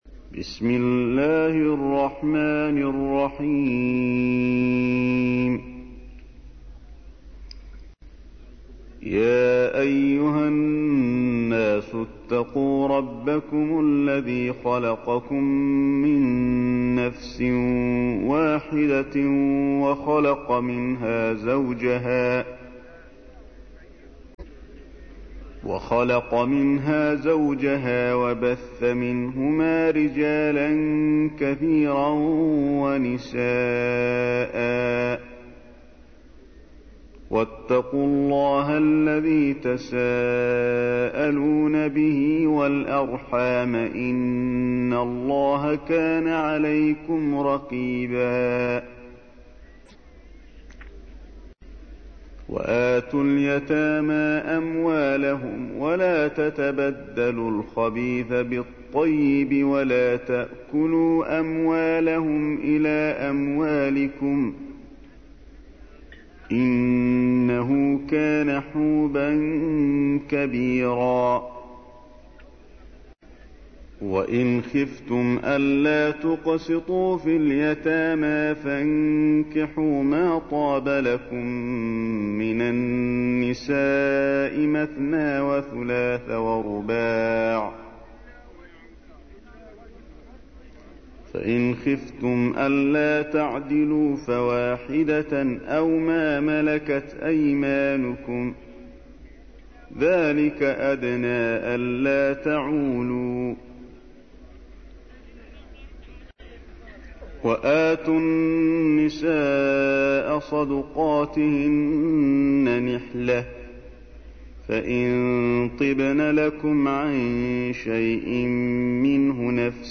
تحميل : 4. سورة النساء / القارئ علي الحذيفي / القرآن الكريم / موقع يا حسين